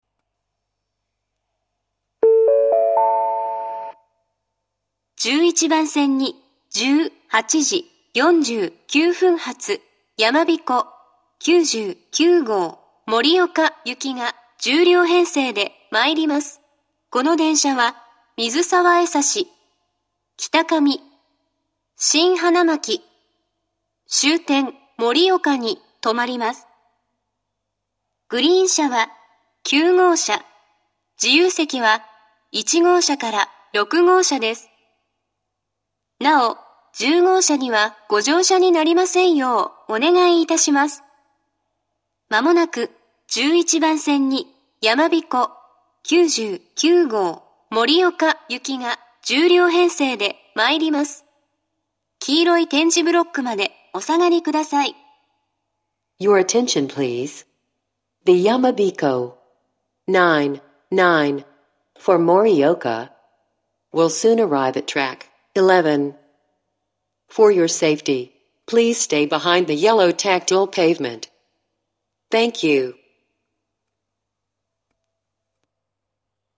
２０２１年１月下旬頃には、自動放送が合成音声に変更されました。
１１番線接近放送